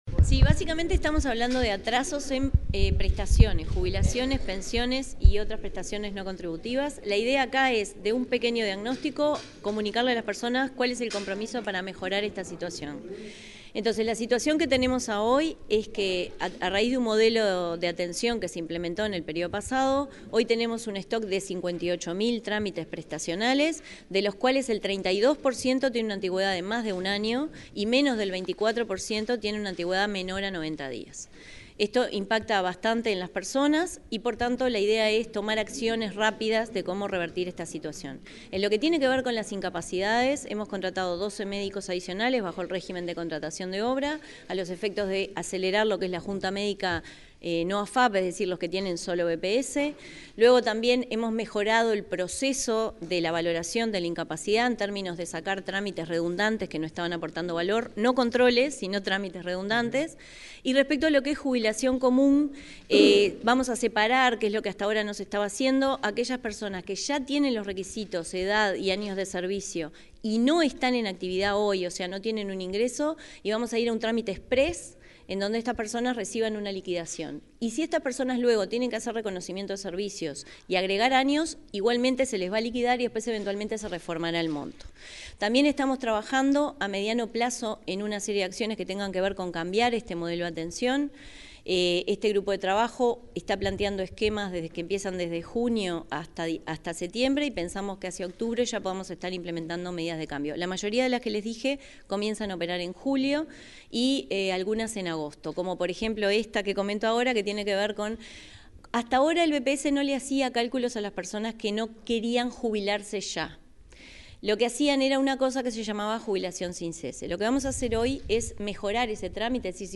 Declaraciones de la presidenta del BPS, Jimena Pardo
Declaraciones de la presidenta del BPS, Jimena Pardo 30/06/2025 Compartir Facebook X Copiar enlace WhatsApp LinkedIn La presidenta del Banco de Previsión Social (BPS), Jimena Pardo, realizó declaraciones a los medios de prensa, tras presentar las medidas para mejorar la gestión de trámites y reducir la cantidad de expedientes atrasados.